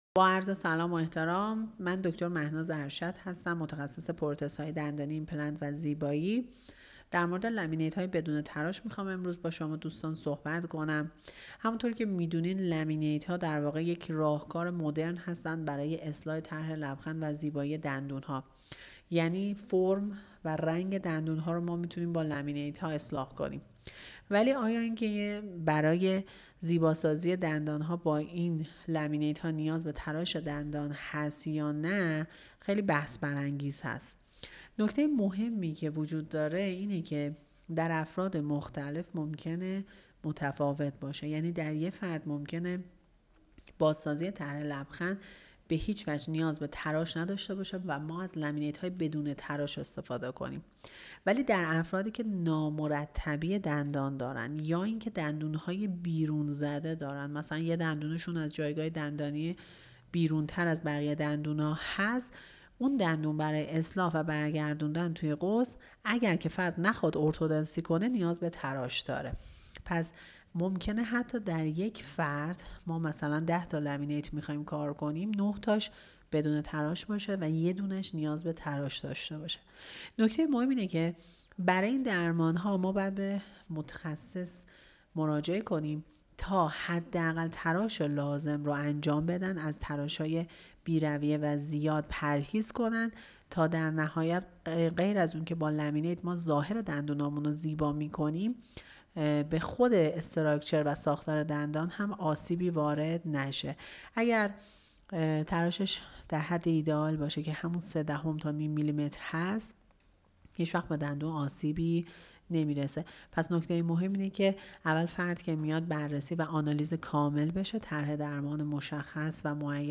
صحبت‌های تخصصی